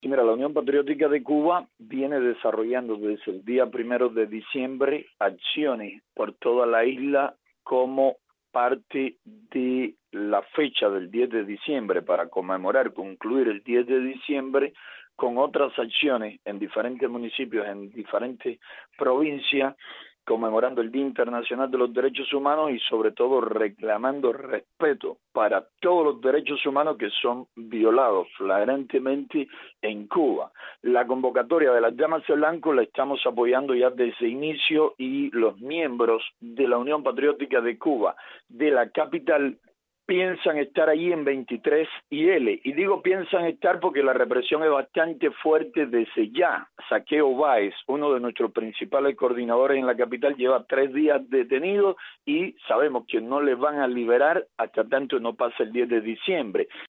Declaraciones de José Daniel Ferrer